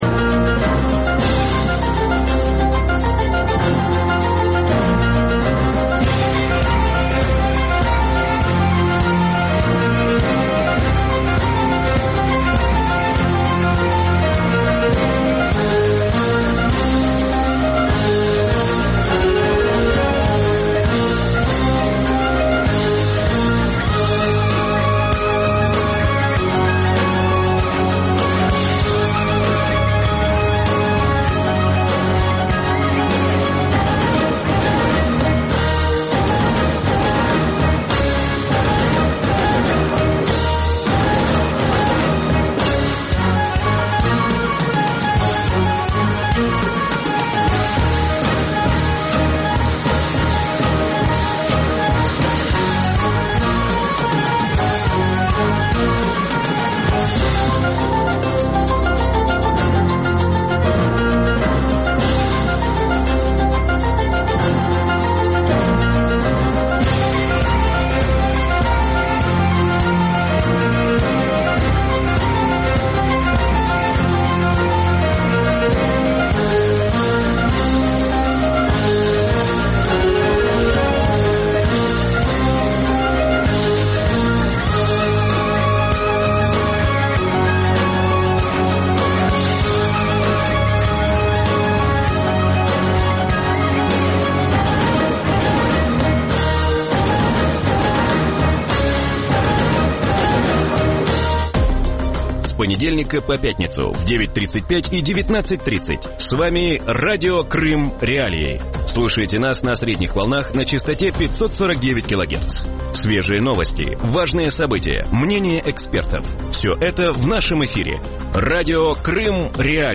В вечернем эфире Радио Крым.Реалии говорят о годовщине окончания Ялтинской конференции 1945 года и возможности распределения мировых сфер влияния сегодня. Готов ли мир возвратиться к внешнеполитическим моделям ХХ века и могут ли Украина и Крым стать разменными монетами для заключения большой сделки?
Ведущий программы – Виталий Портников.